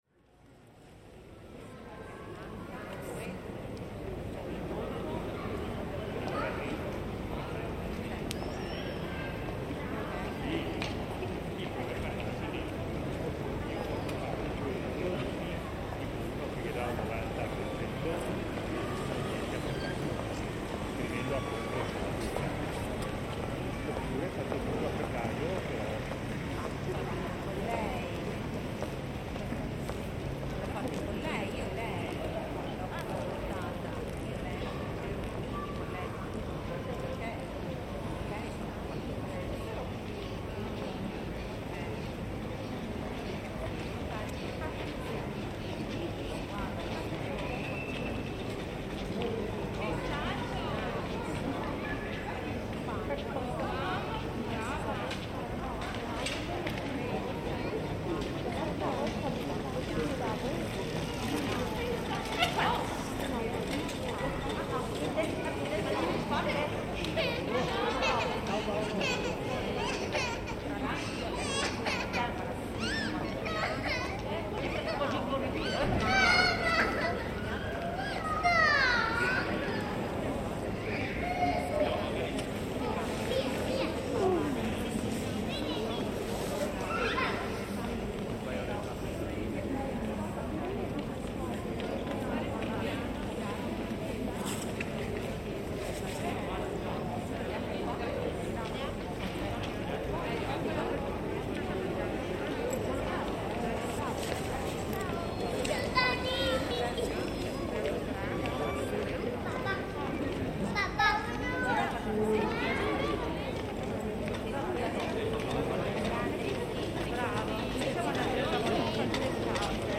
Bells at 12 noon recorded in the magnificent Palladian Piazza dei Signori in Vicenza - binaural recording also featuring the surrounds sounds of passers-by and children playing.